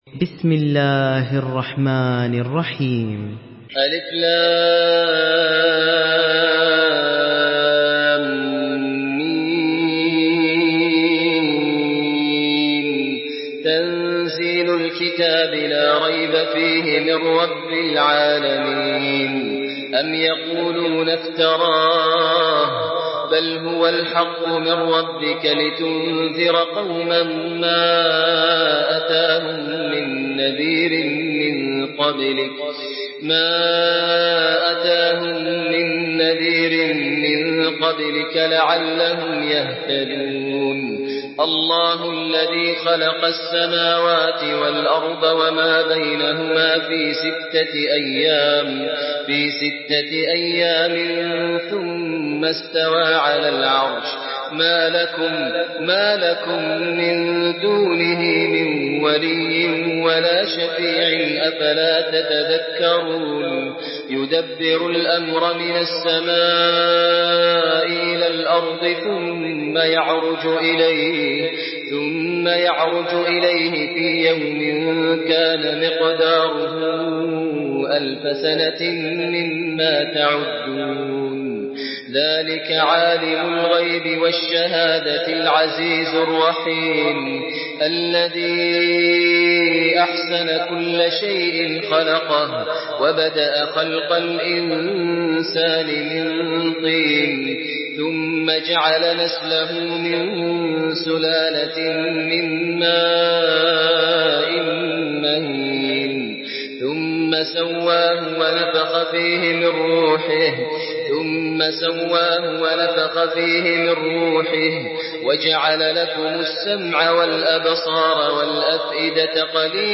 Surah আস-সাজদা MP3 by Maher Al Muaiqly in Hafs An Asim narration.